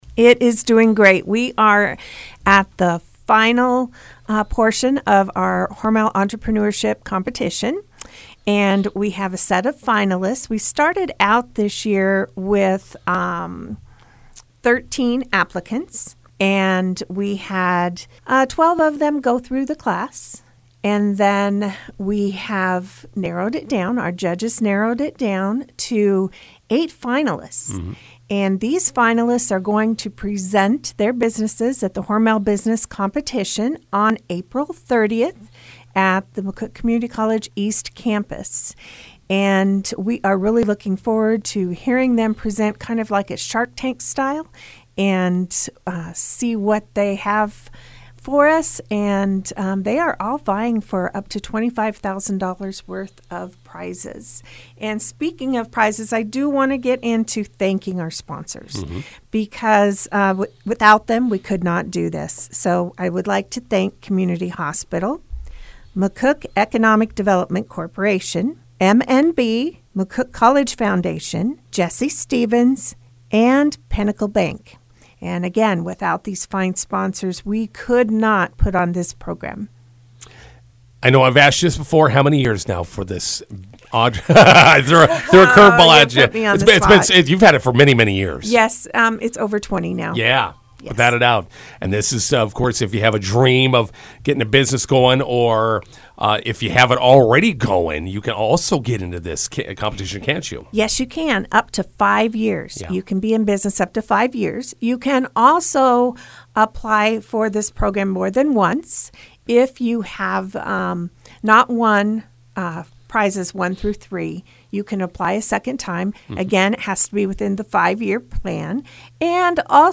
INTERVIEW: Hormel Entrepreneurship Competition Finalists announced.